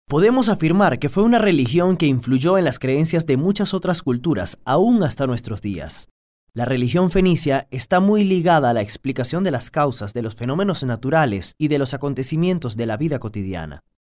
Venezuelan male voice talent